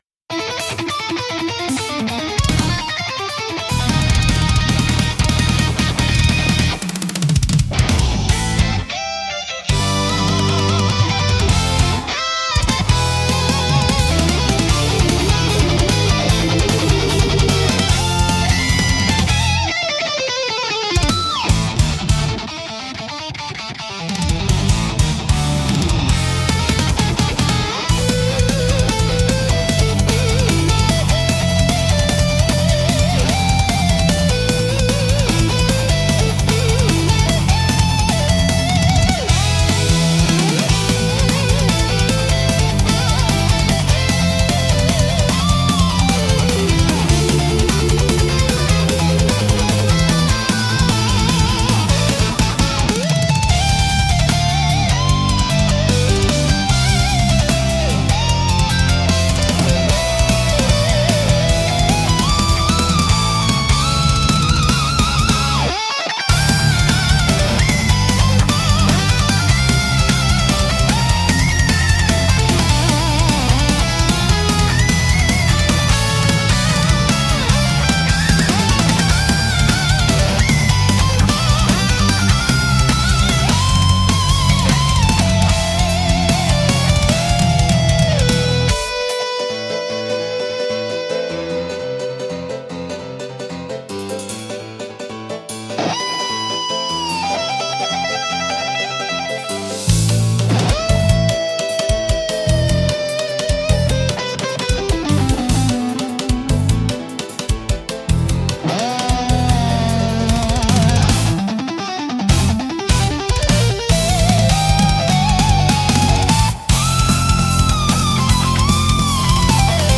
熱狂的で迫力あるサウンドが勝負や決戦の場面を盛り上げ、プレイヤーや視聴者の集中力と興奮を引き出します。